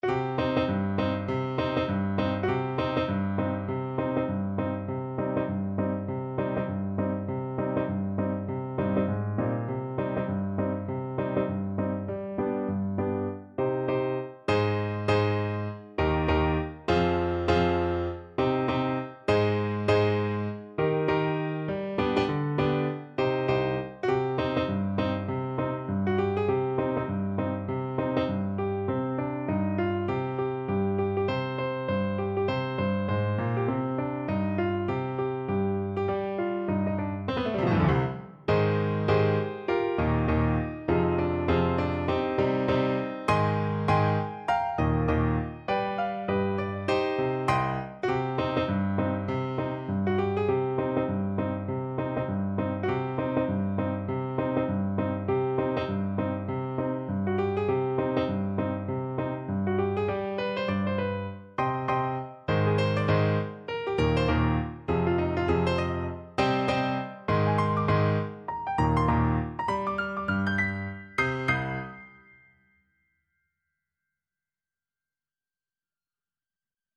Firmly, with a heart of oak! Swung = c.100
Traditional (View more Traditional Soprano Saxophone Music)